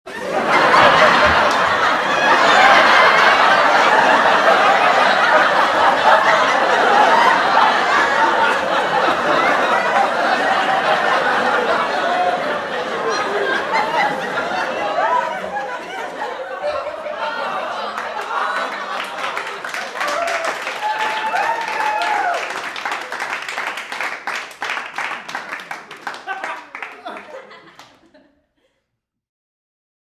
Sitcom Laughter Applause